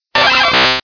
P3D-Legacy / P3D / Content / Sounds / Cries / 141.wav